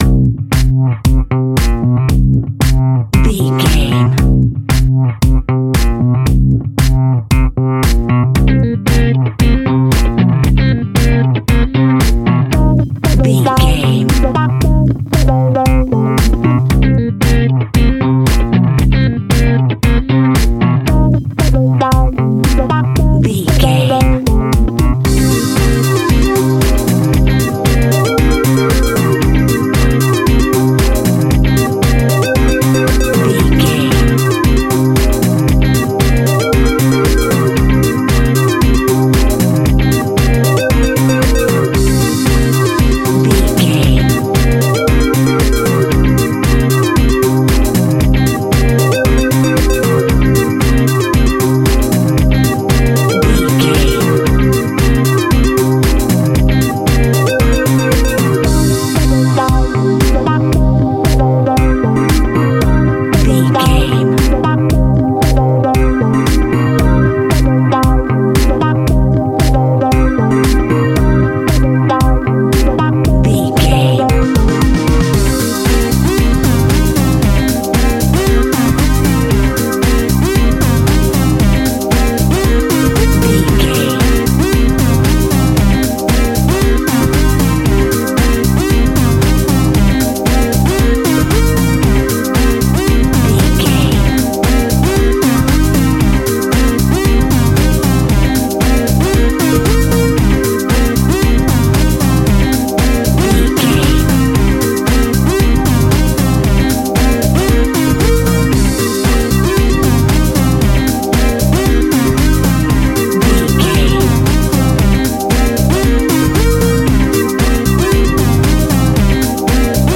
Old Skool Funky House.
Aeolian/Minor
groovy
dark
energetic
driving
bass guitar
drums
drum machine
synthesiser
disco
nu disco
upbeat
instrumentals
funky guitar
clavinet
synth bass
horns